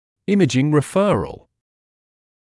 [‘ɪmɪʤɪŋ rɪ’fɜːrəl] [‘имиджин ри’фёːрэл]